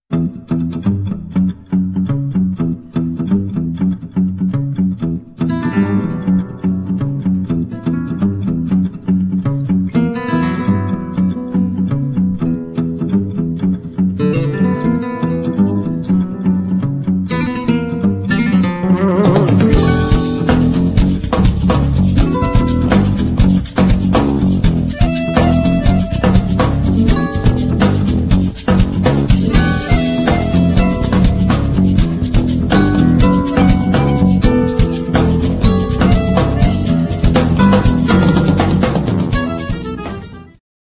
gitara akustyczna / acoustic guitar